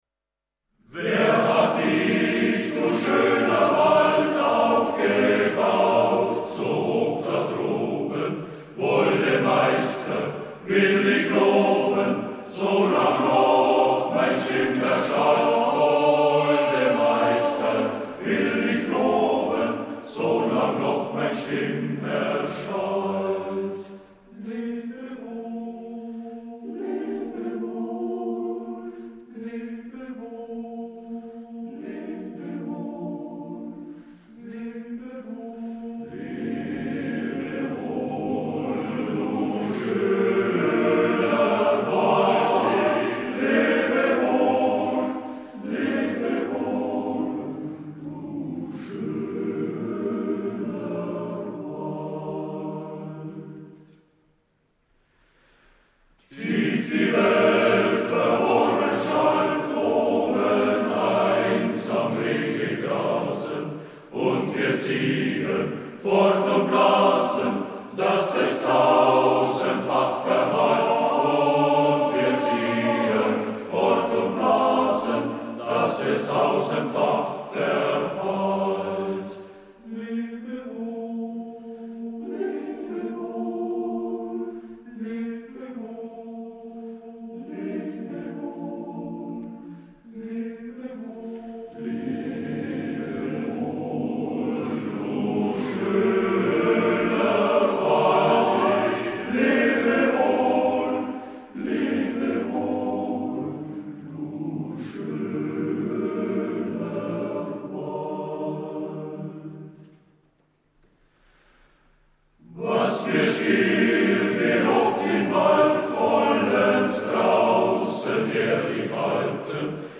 JUBILÄUMSKONZERT AM